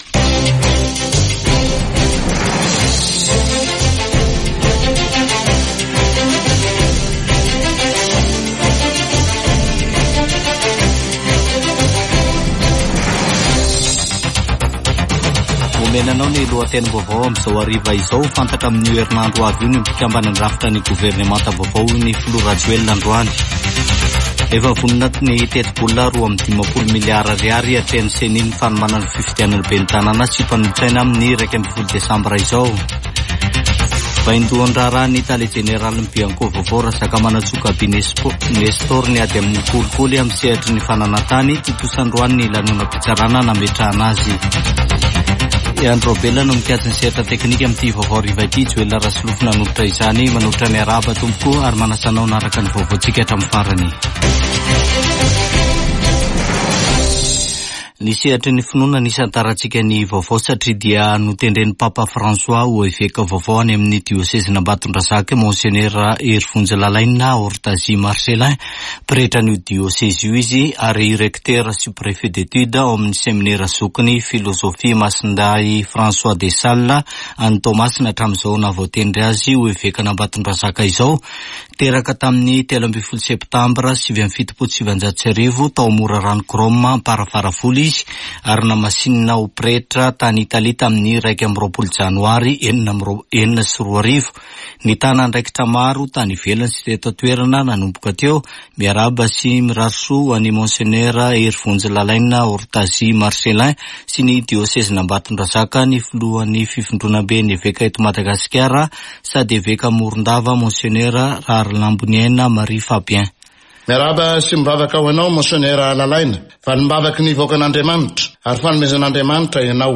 [Vaovao hariva] Alarobia 14 aogositra 2024